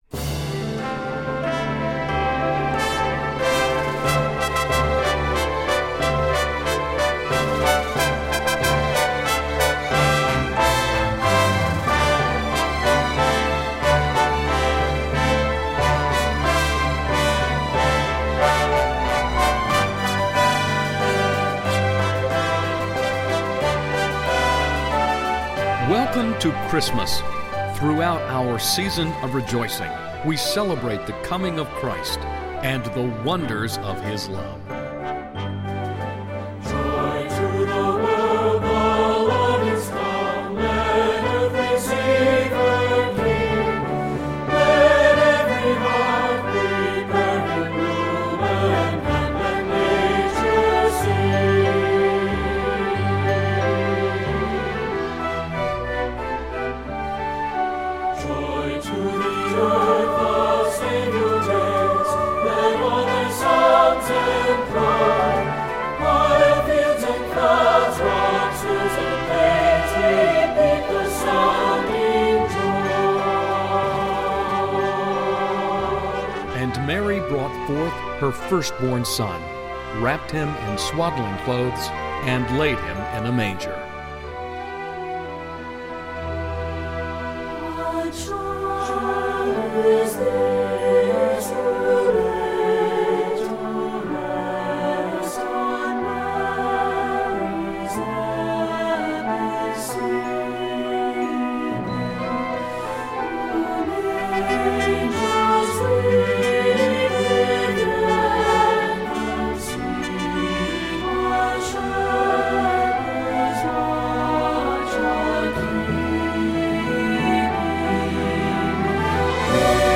Voicing: SATB, Congregation and Narrator